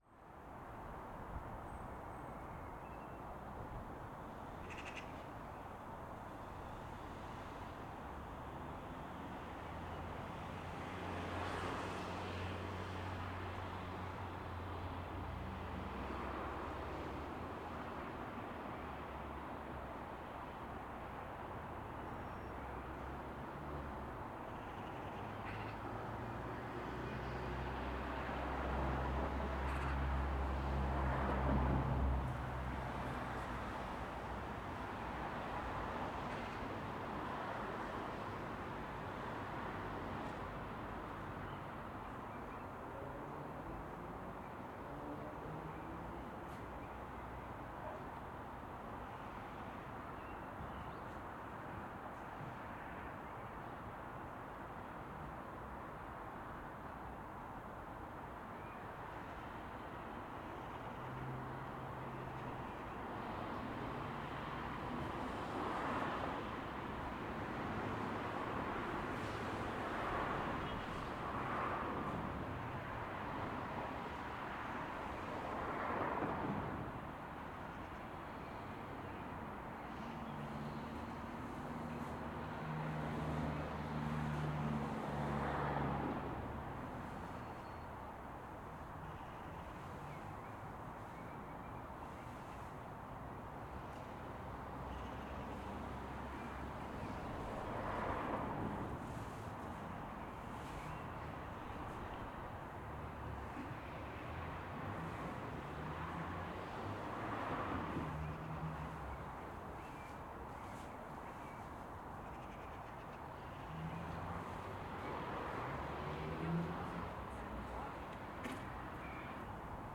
AMBSubn_Residential Area, Street, Light Distant Traffic,_344 Audio_UK Residential & Industrial.ogg